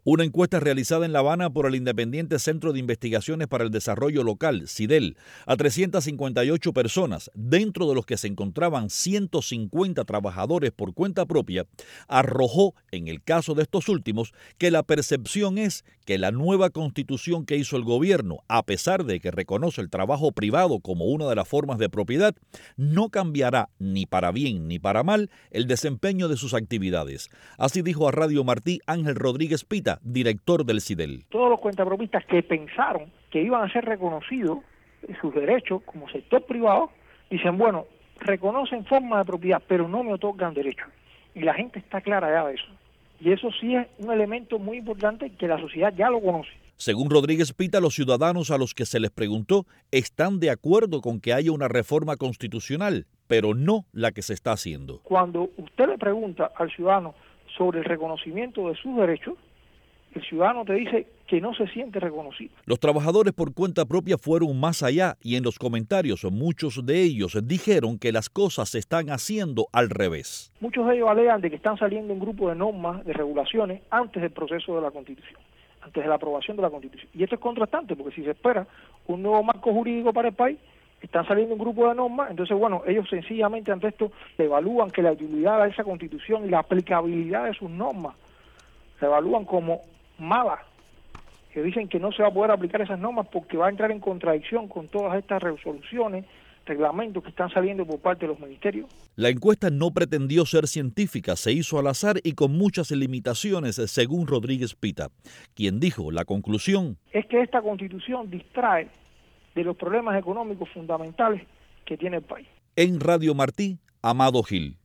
conversó con el director del proyecto